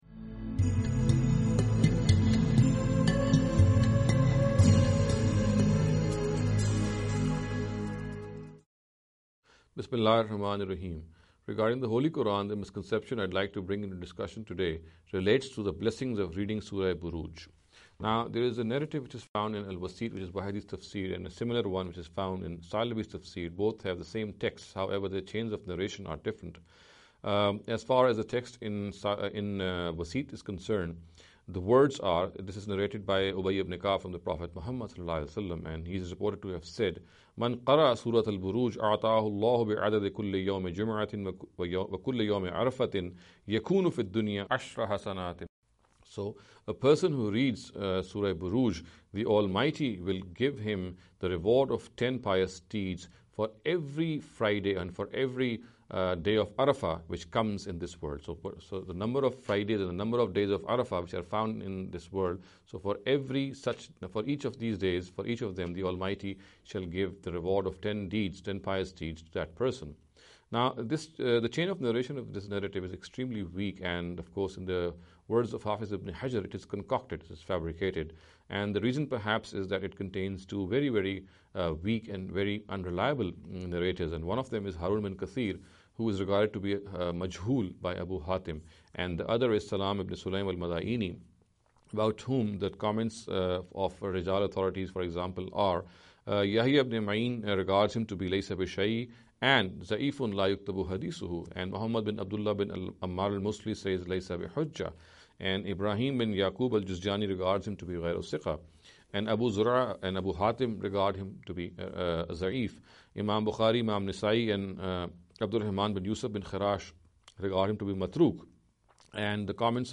Associate Speakers